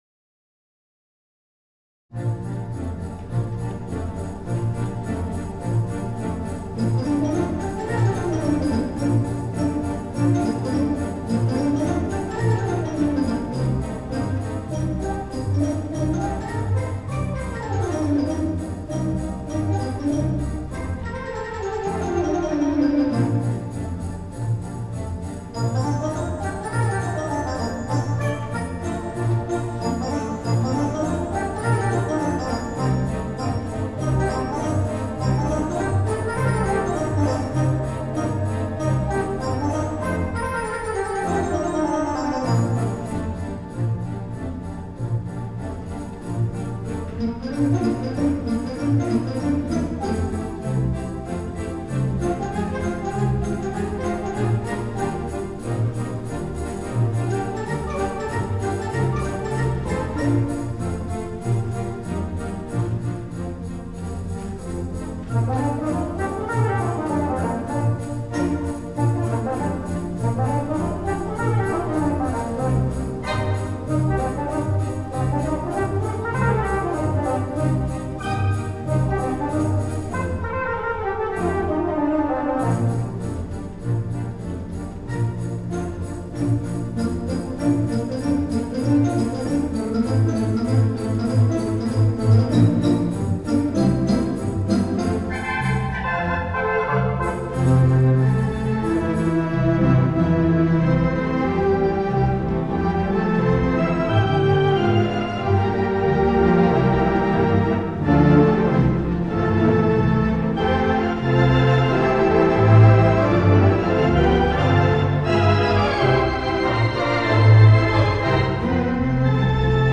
The Mighty WurliTzer on the stage